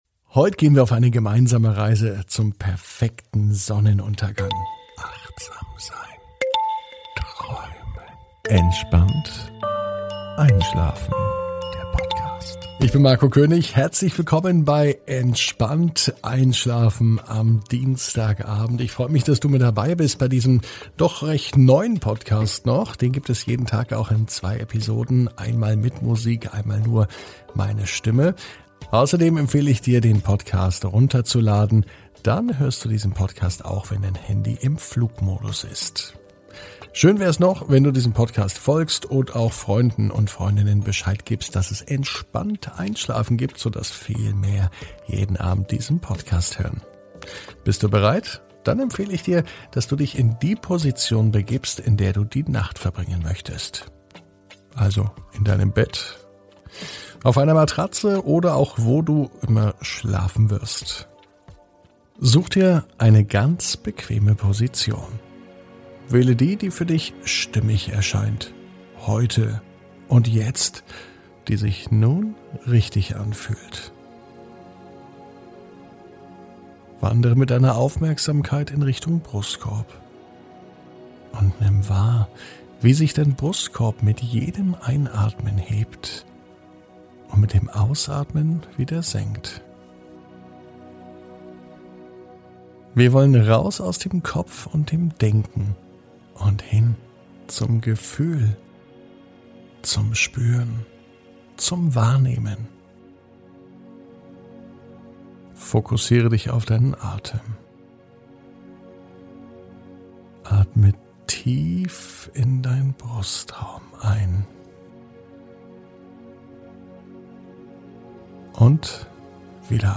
Entspannt einschlafen am Dienstag, 11.05.21 ~ Entspannt einschlafen - Meditation & Achtsamkeit für die Nacht Podcast